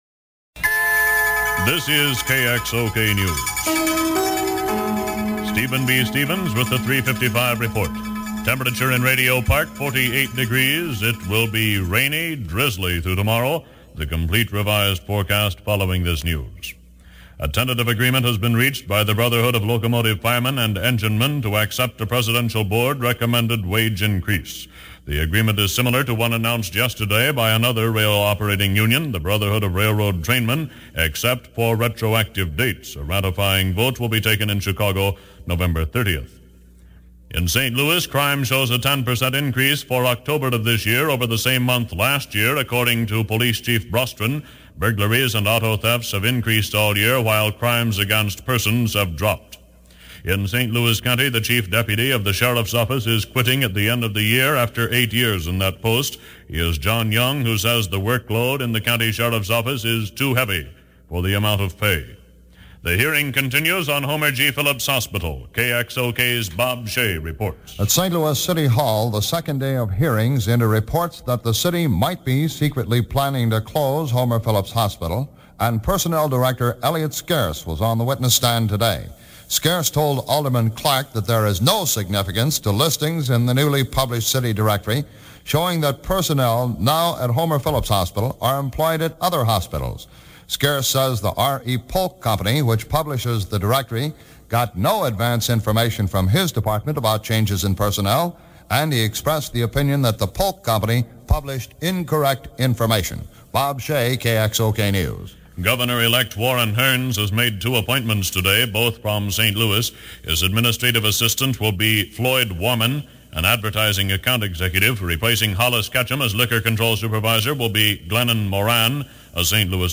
KXOK was a prominent radio station in St. Louis in the early-1960s. KXOK was known for its Top 40 format and was especially popular among teens and young adults.
AUDIO REMASTERED!